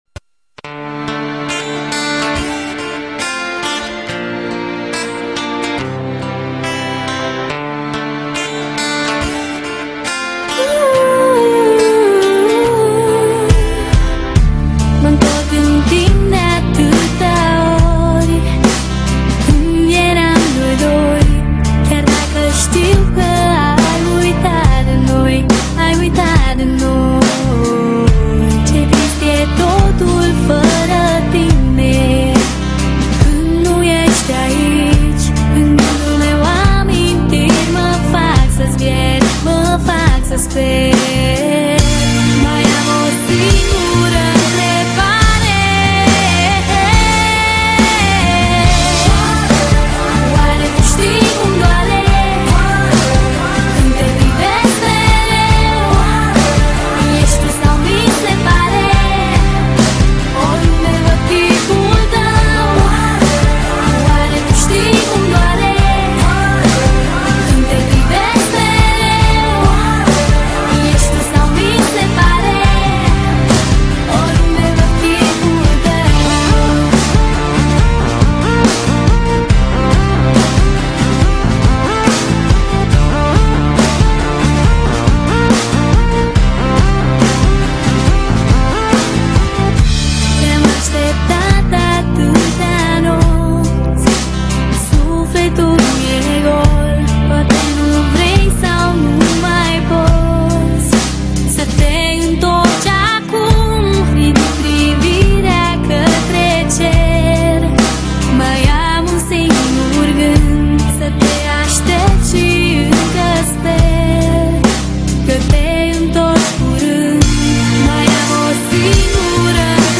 Жанр:Club/Dance